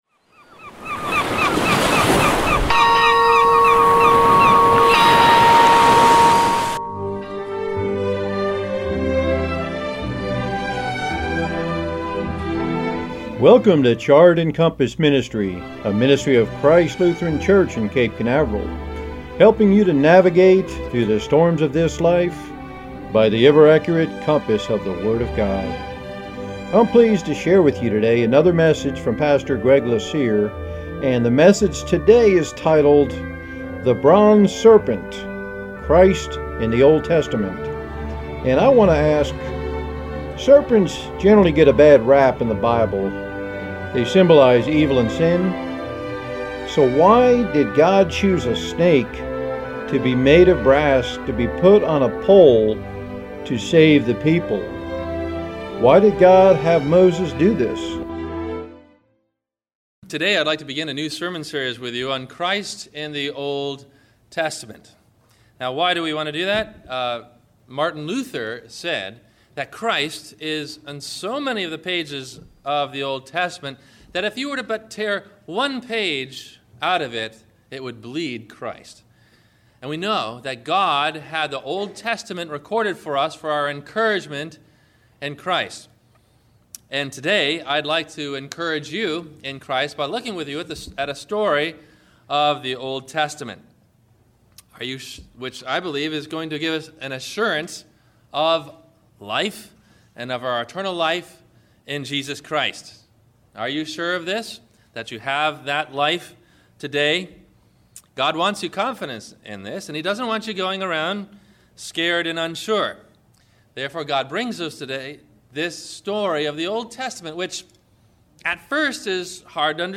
The Bronze Serpent - Christ in the Old Testament – WMIE Radio Sermon – June 20 2016 - Christ Lutheran Cape Canaveral
Questions asked before the message: